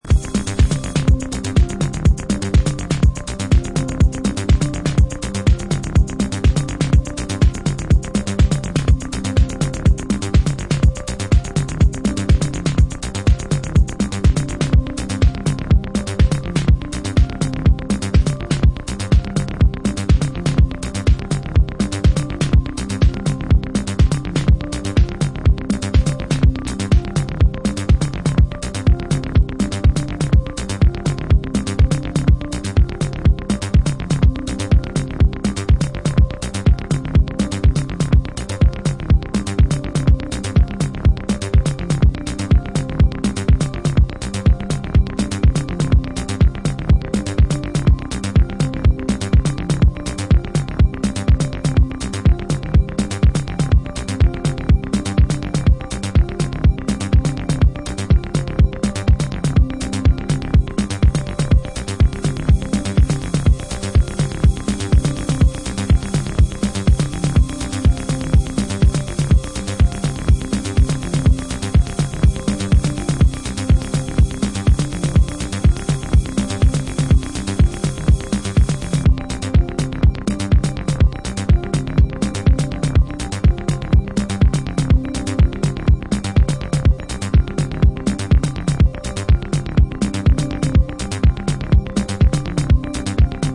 シカゴ・ ハウス注目の次世代気鋭プロデューサー